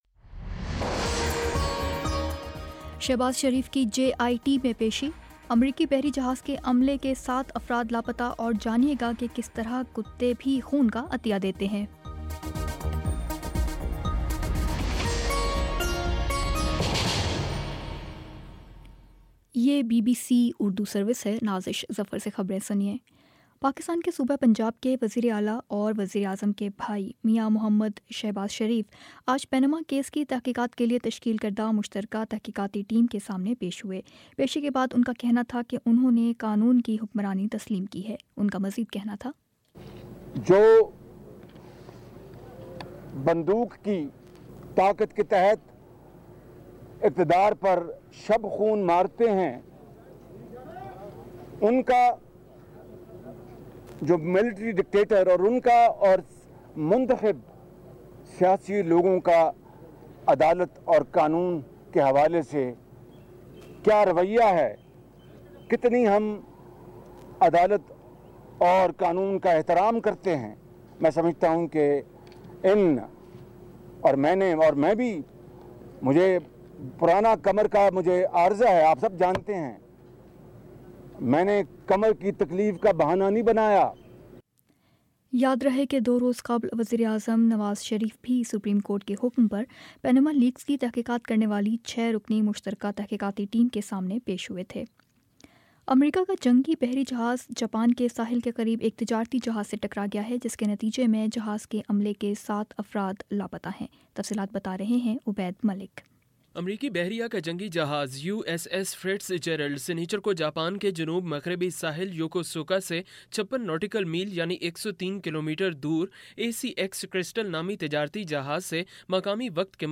جون 17 : شام سات بجے کا نیوز بُلیٹن